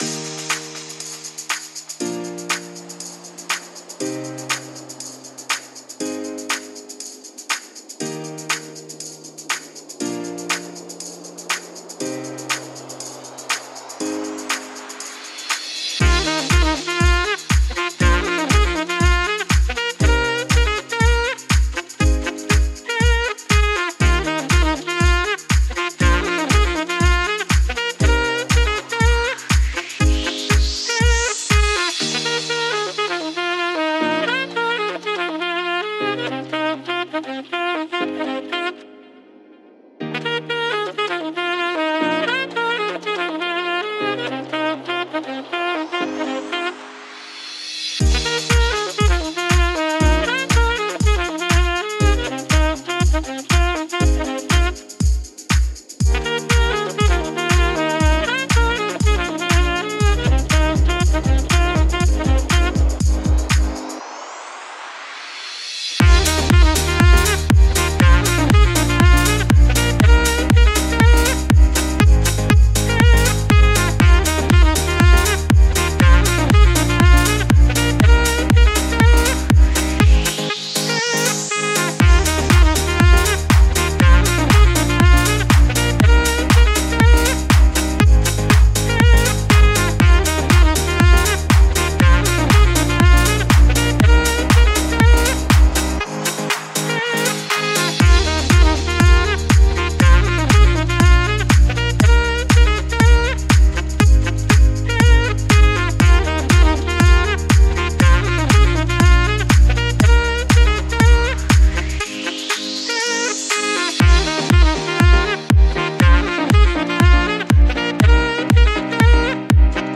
Saxophone
دانلود آهنگ فوق العاده زیبا ساکسفون